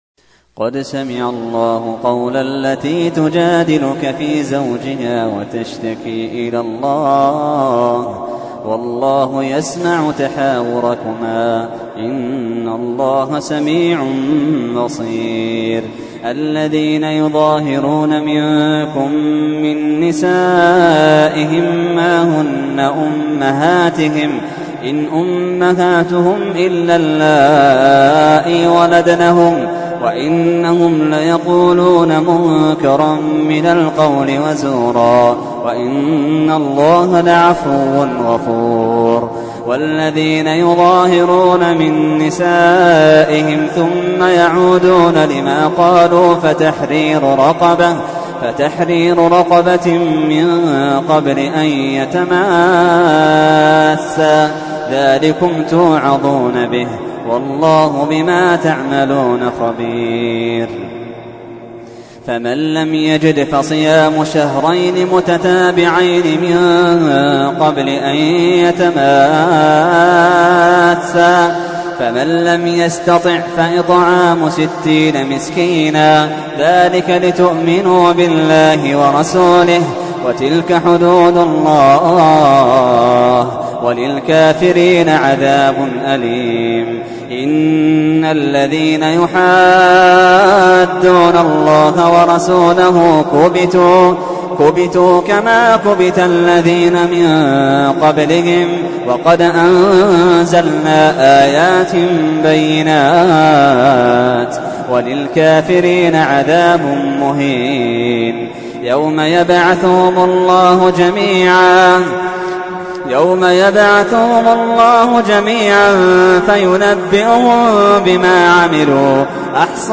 تحميل : 58. سورة المجادلة / القارئ محمد اللحيدان / القرآن الكريم / موقع يا حسين